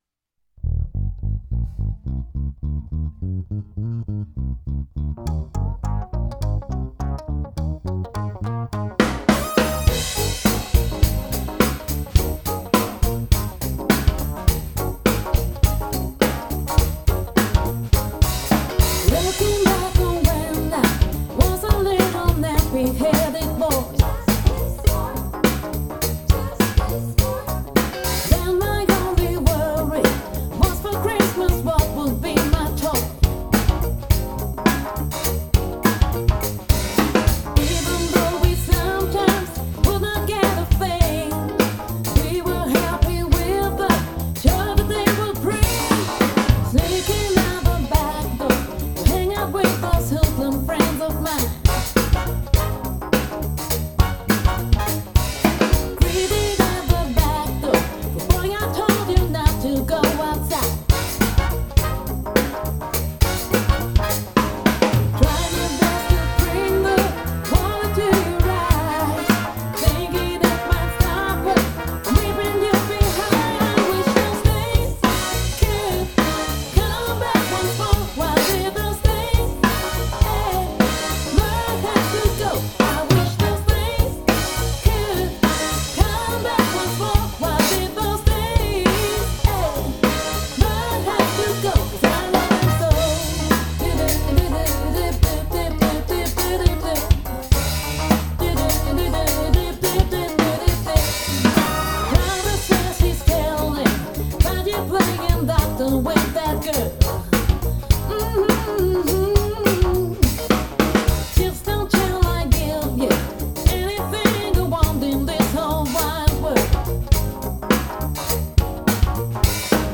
derjenige, für den ich in der session was eingetrommelt hatte, hat mir ungefragt n mix von der Nummer geschickt, weil die Daten halt mit im Projekt waren. wem die videoversion nicht genug geknallt hat:http